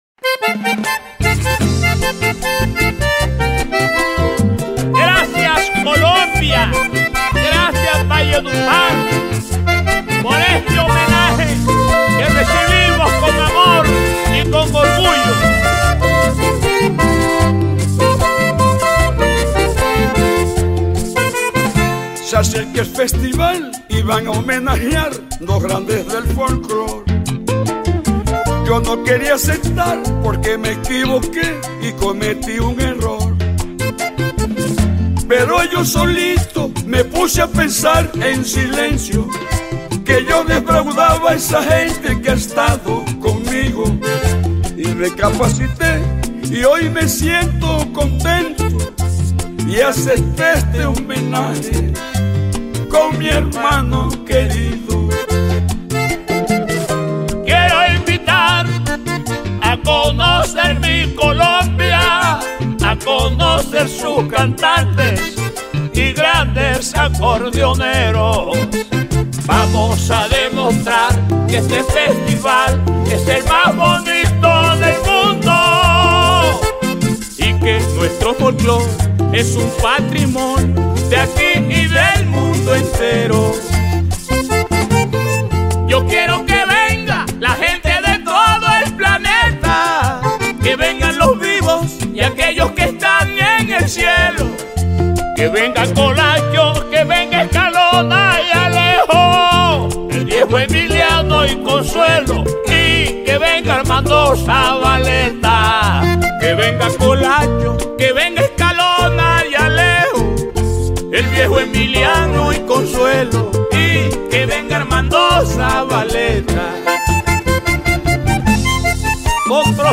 Ritmo: paseo.